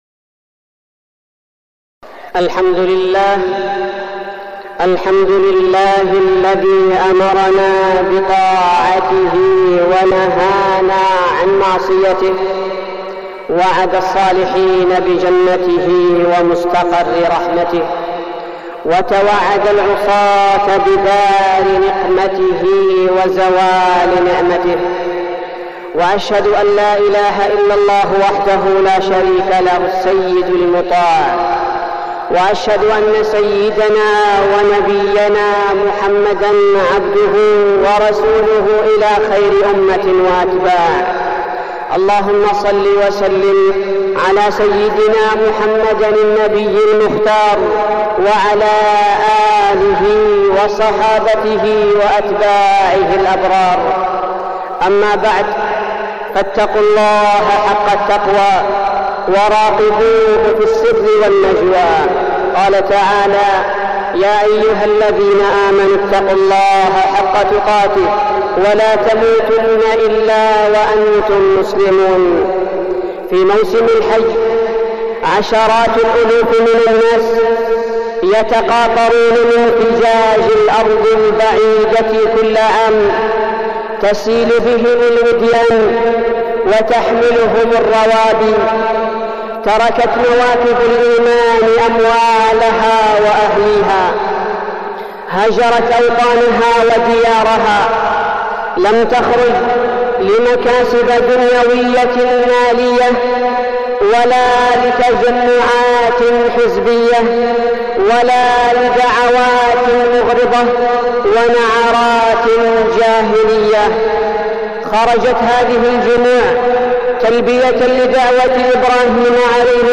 تاريخ النشر ١٧ ذو القعدة ١٤١٩ هـ المكان: المسجد النبوي الشيخ: فضيلة الشيخ عبدالباري الثبيتي فضيلة الشيخ عبدالباري الثبيتي الحج المبرور